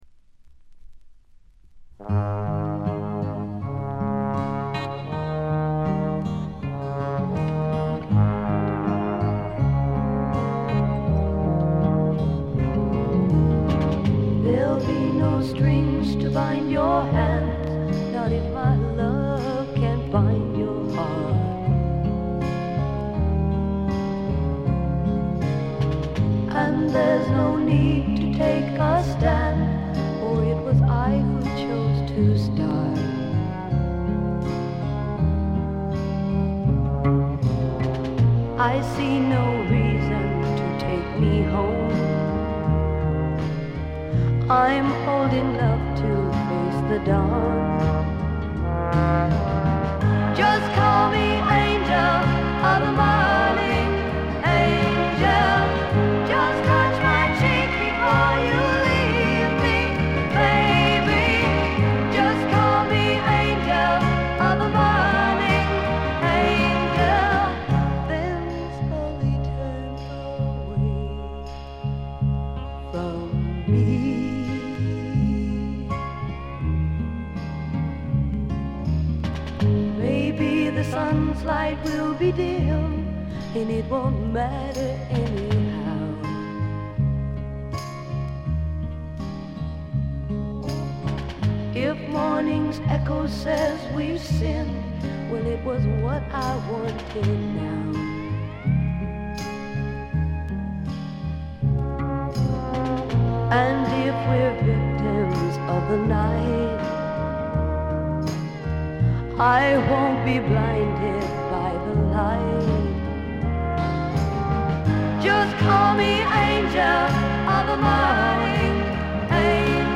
部分試聴ですが、わずかなノイズ感のみ。
試聴曲は現品からの取り込み音源です。